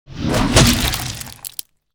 dragonclaw.wav